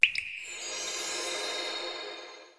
snd_ui_enterGameBtn.wav